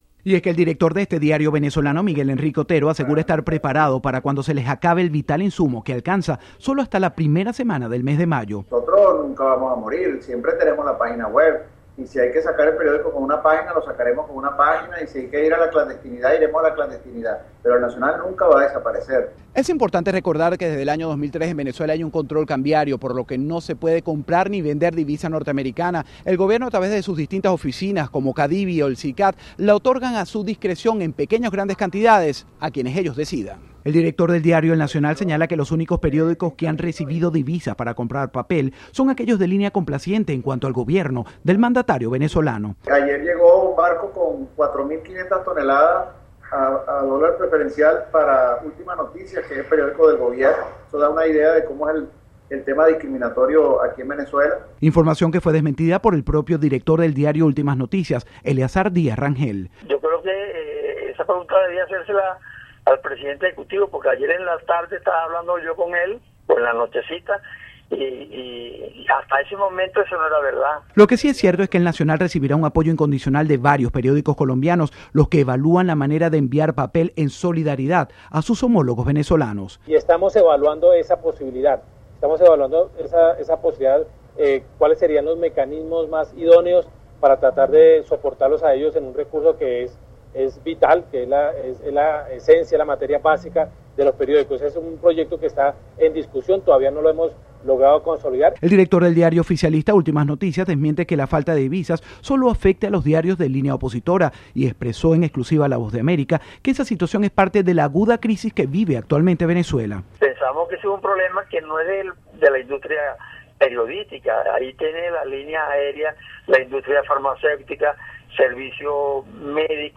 El diario venezolano El Nacional, opositor al gobierno de Nicolás Maduro, se niega a dejar de circular pese a no tener reservas de papel. Informa desde Miami